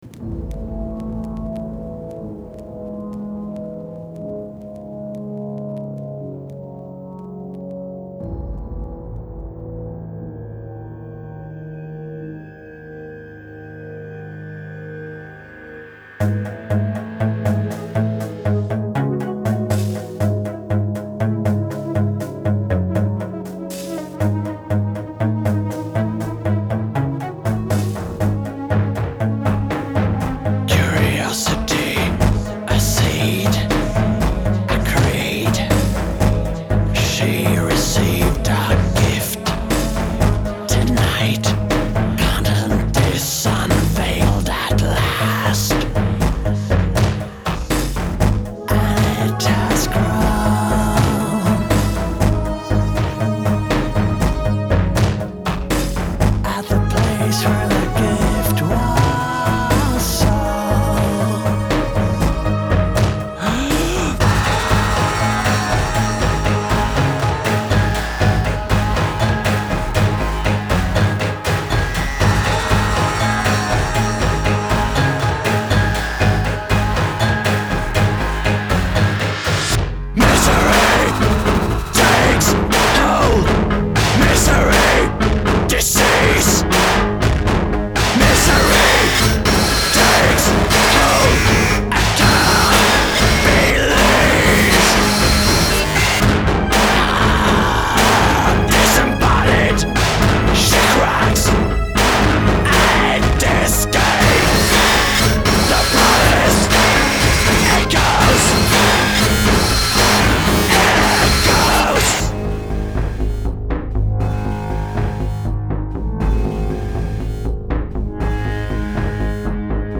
Genre: EBM/Industrial